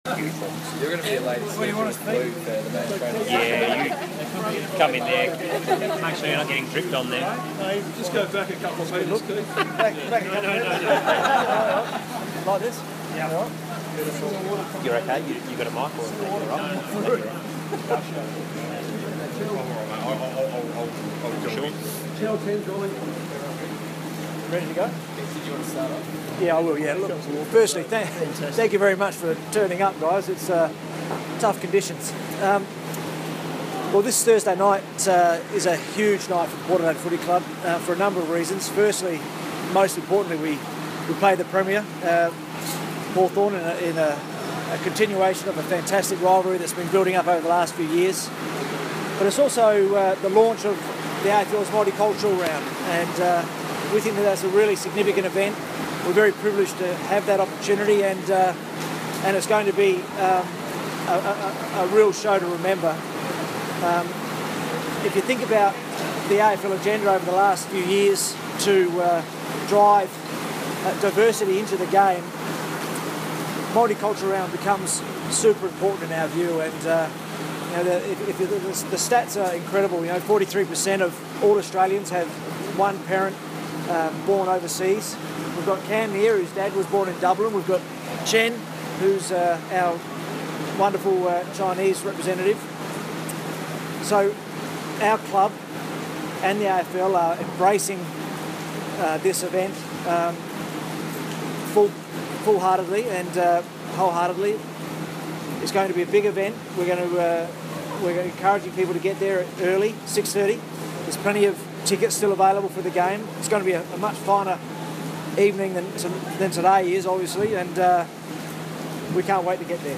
Multicultural Round press conference - Monday, 4 July, 2016